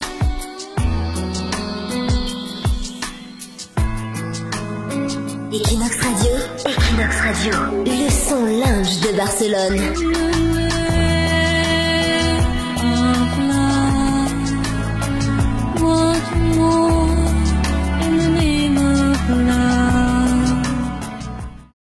Identificació de la ràdio